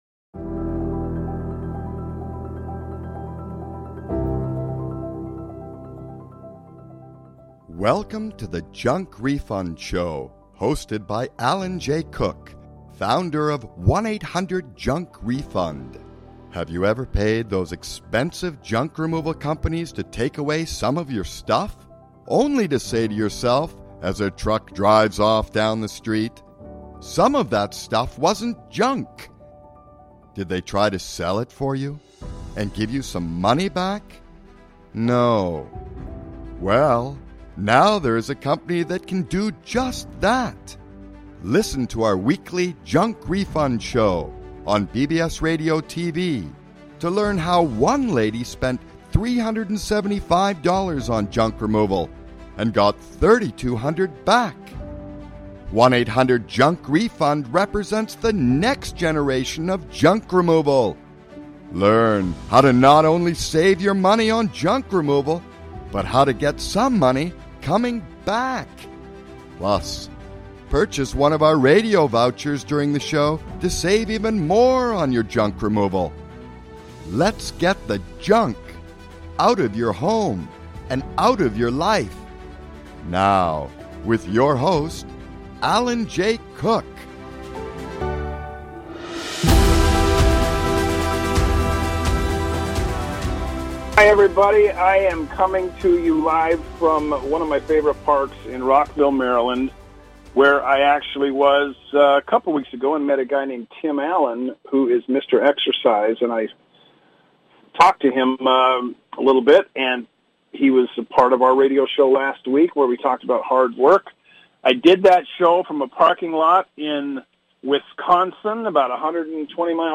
Talk Show Episode, Audio Podcast, Junk Refund Show and Kindness and Compassion: How to Work with Hoarders.